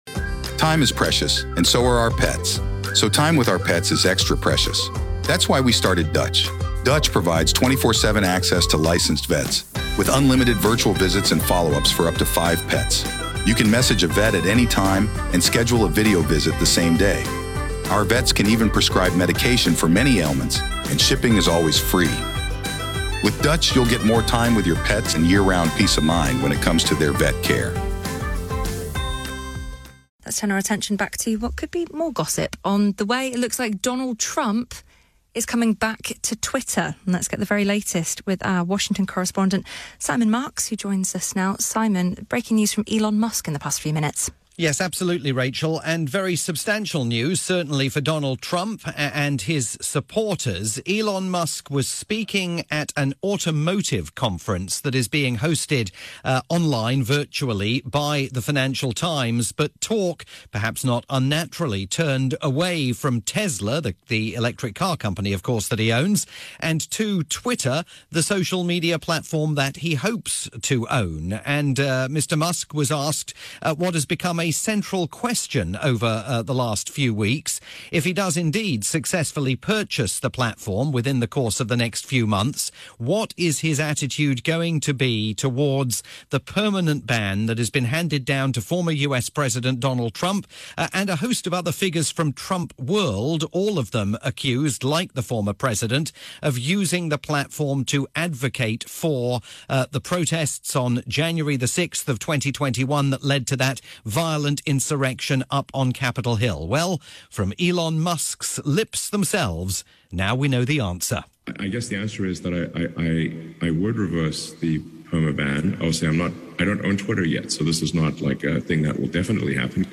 breaking news report